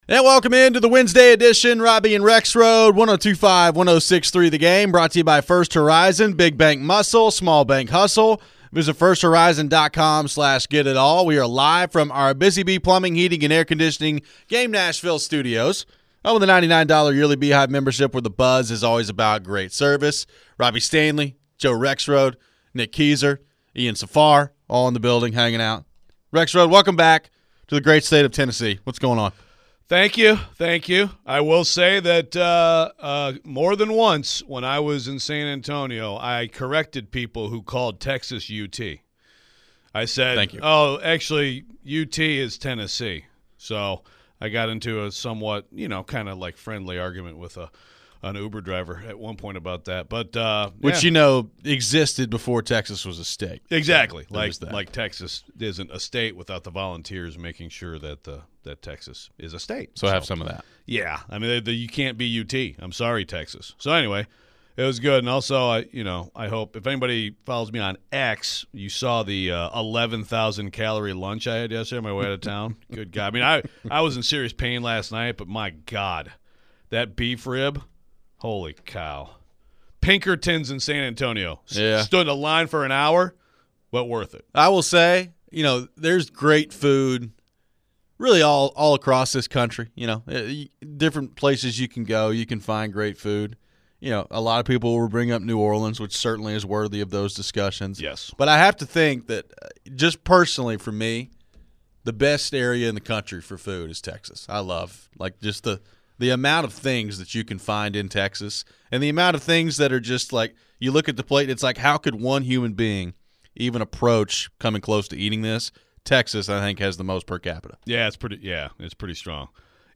We take your phones. We finish up the hour with some thoughts on the NBA and have they become too reliant on the 3-point shot.